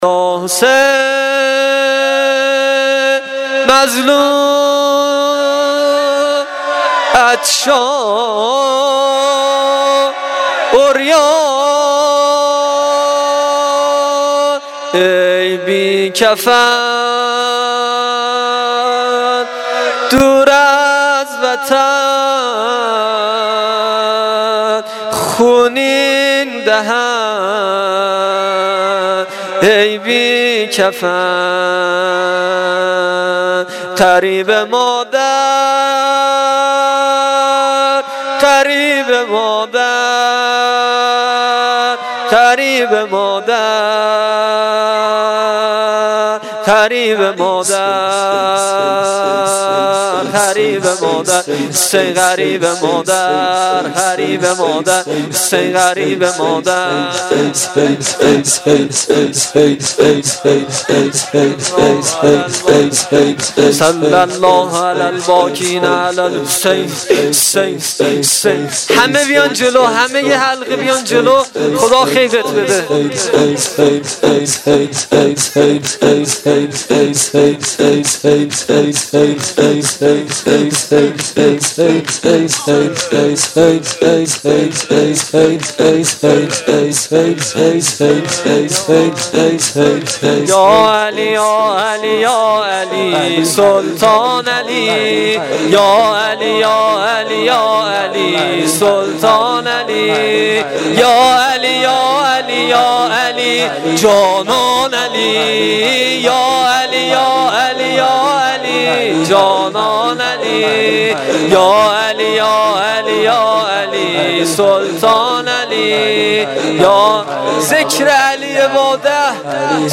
شور شب 21 ماه رمضان